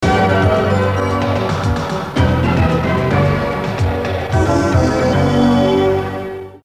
Promo INTRO